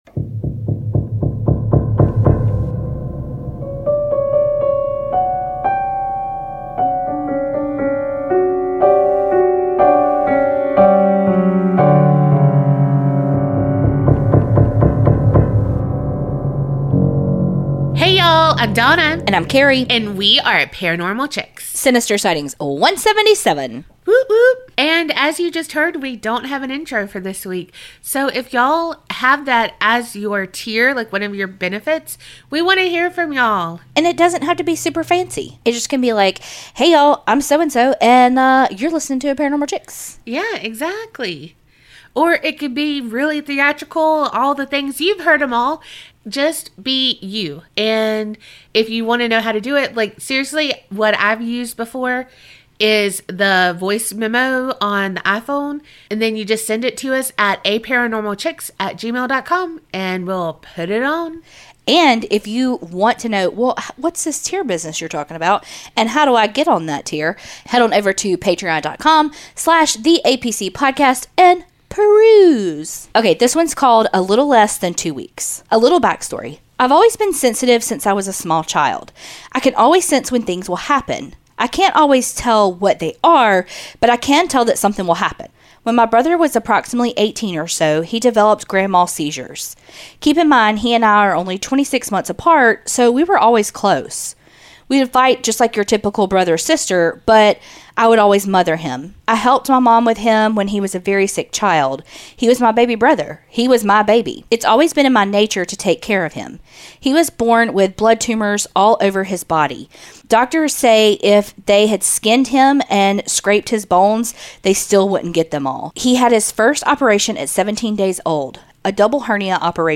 Sinister Sightings are your true crime and true paranormal stories. Every week we read out ones that you've sent in.